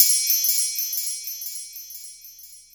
Belltree.wav